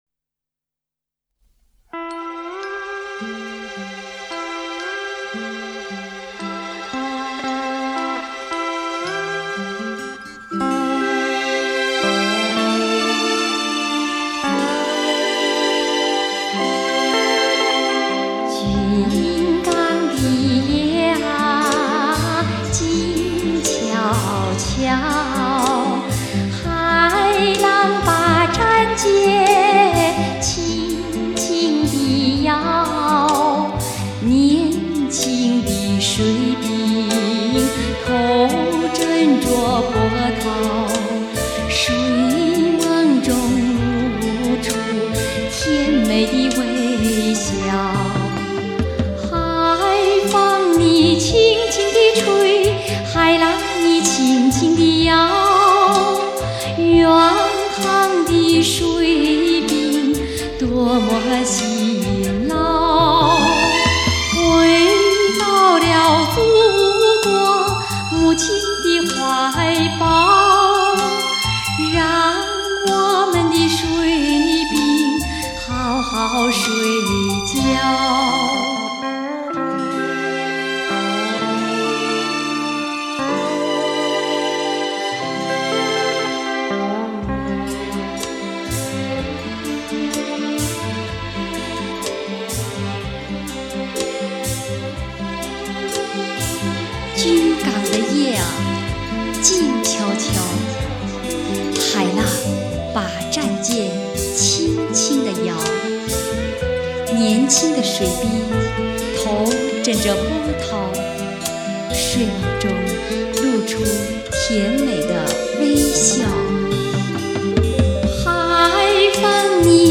那歌声...那旋律...悠扬飘荡...